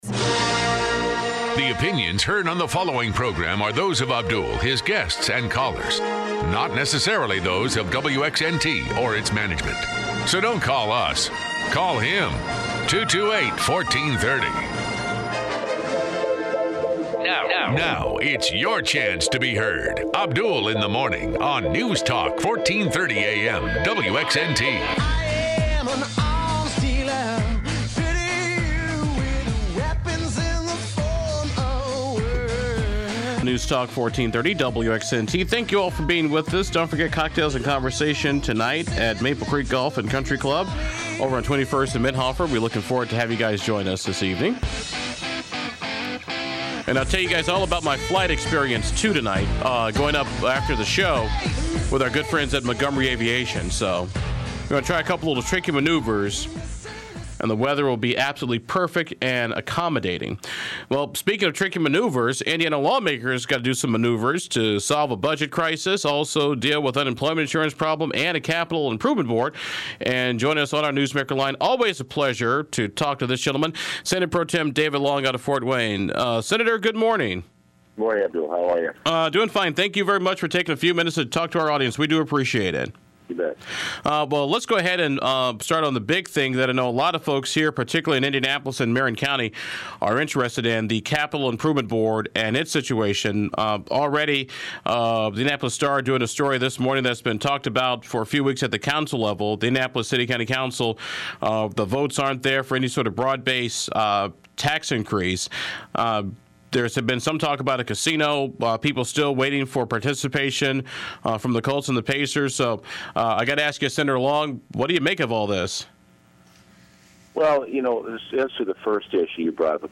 You can here the entire interview below as Long commenting on the budget and the state’s unemployment trust fund shortfall.